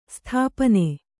♪ sthāpane